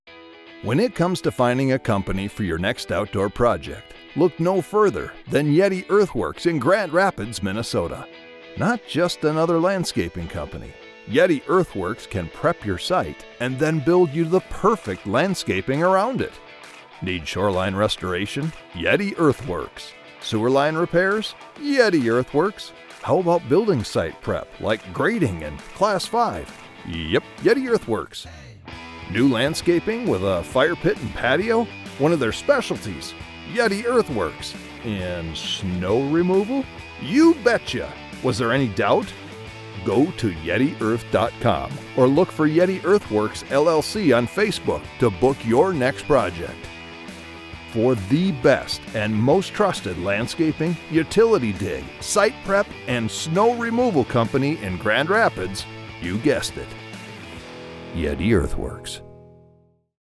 My voice has been described as rich, articulate, and engaging.
Friendly Landscape Company Commercial
English - Midwestern U.S. English
I use a Sennheiser MKH 416 mic in a custom home studio.